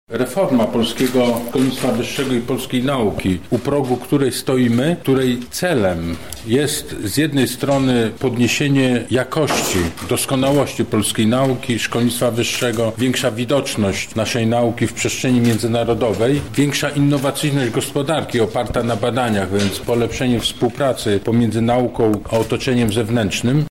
By je rozwiać Aleksander Bobko, sekretarz stanu w Ministerstwie Nauki i Szkolnictwa Wyższego spotkał się dziś z przedstawicielami lubelskich ośrodków akademickich. Wytłumaczył między innymi na czym polegają planowane zmiany: